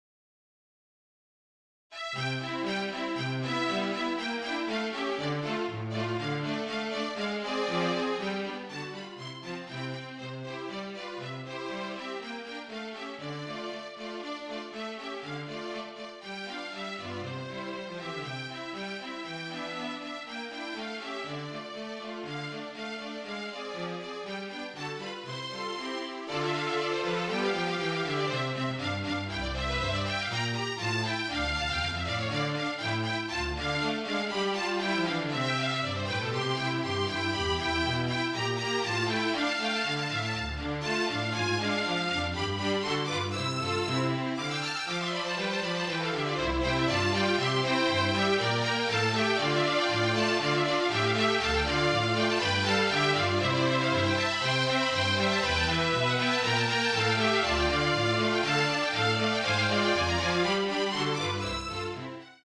Flute, Violin and Cello (or Two Violins and Cello)
MIDI
(Flute Trio version)